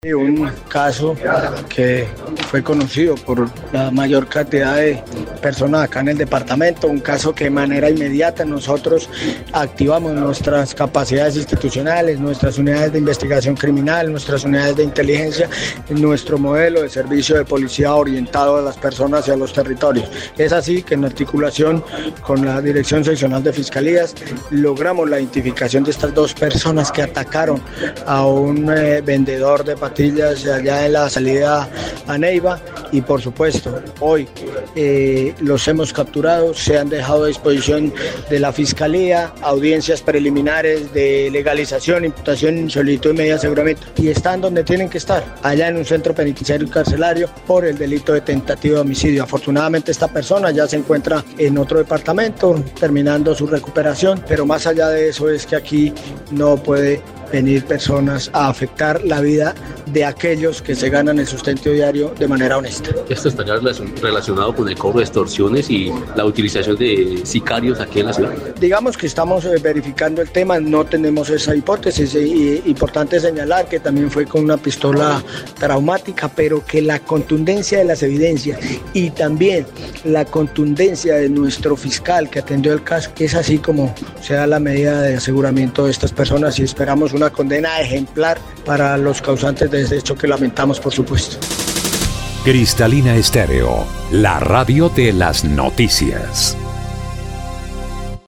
Así lo dio a conocer el comandante de la Policía Caquetá, coronel, Julio Hernando Guerrero Rojas, quien dijo que, las capturas se llevaron a cabo en los barrios La Libertad y Los Alpes, por el delito de tentativa de homicidio, ocurrido el pasado 17 de enero del 2025 en el sector conocido como ‘Parque Turbay’.